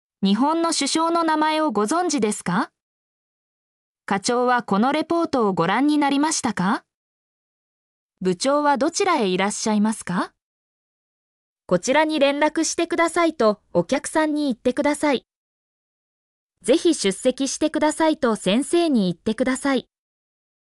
mp3-output-ttsfreedotcom-9_xw05NKo9.mp3